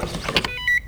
vehicleSwipeID.wav